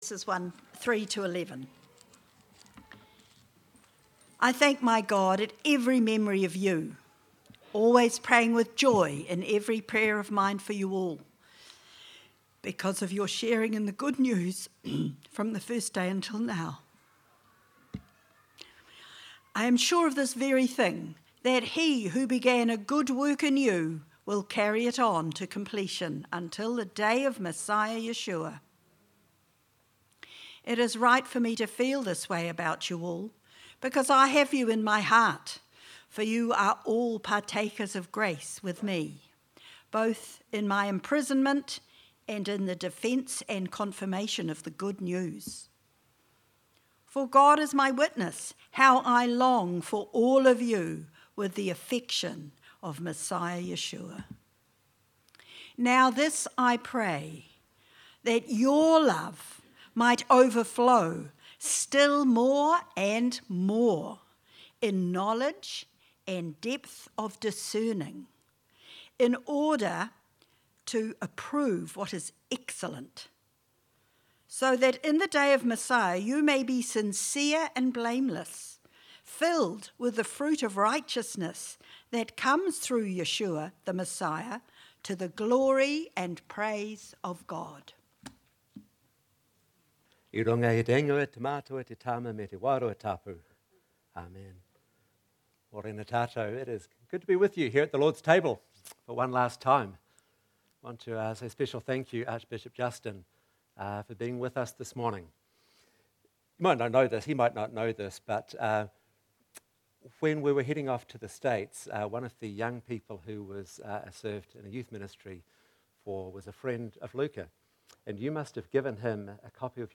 Sermons | All Saints Parish Palmerston North